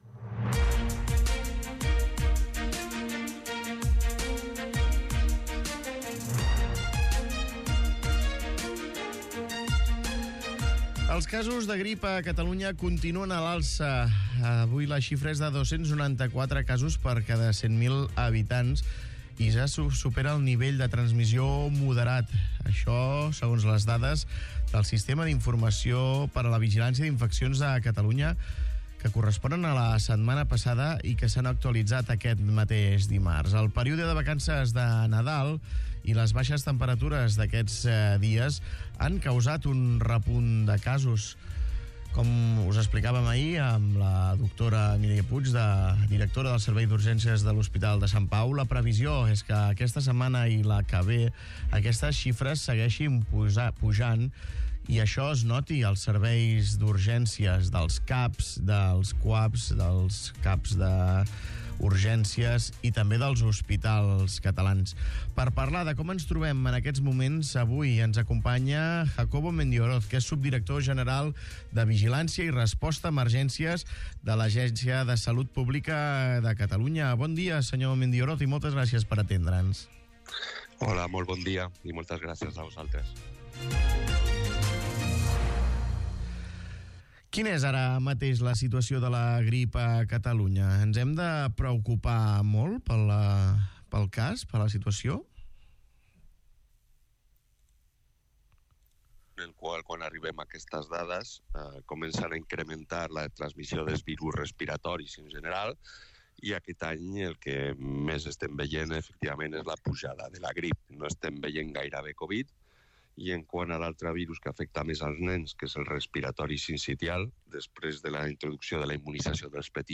Escolta l'entrevista a Jacobo Medioroz, subdirector general de Vigilància Epidemiològica